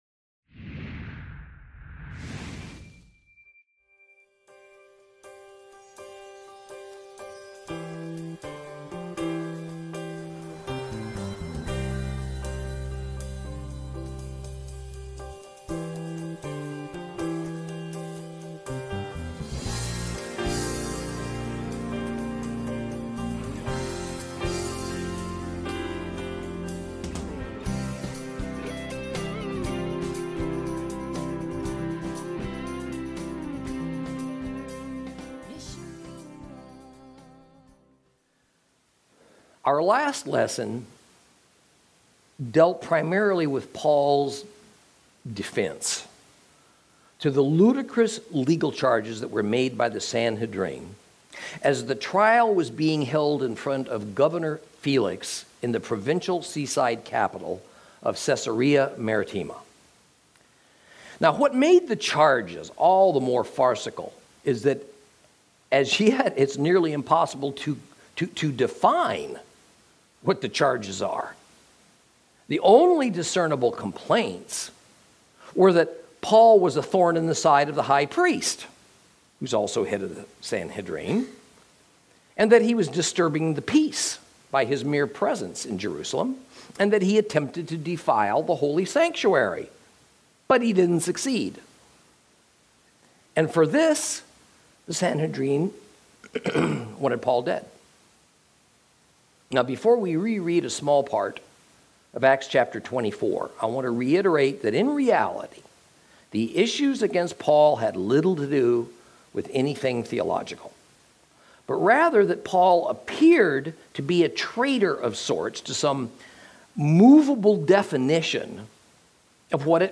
THE BOOKS OF ACTS Lesson 52, Chapters 24 and 25